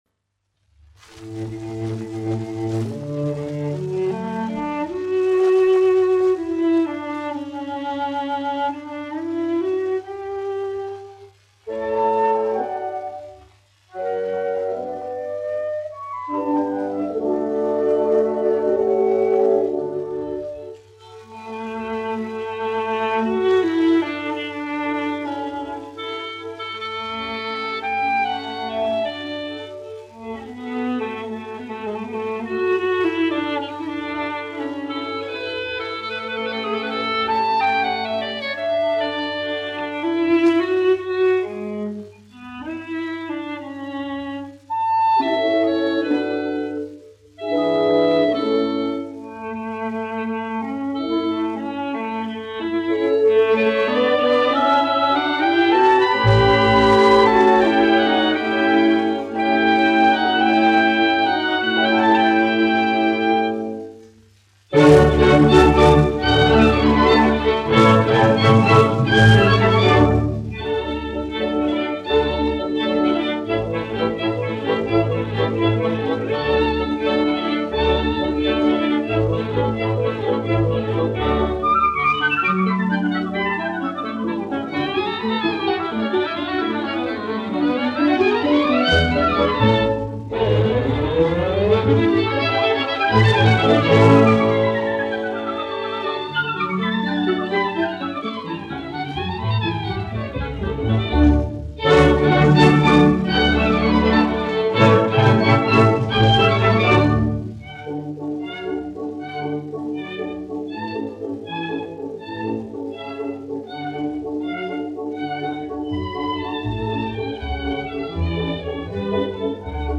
Berliner Konzert-Verein, izpildītājs
1 skpl. : analogs, 78 apgr/min, mono ; 25 cm
Orķestra mūzika
Skaņuplate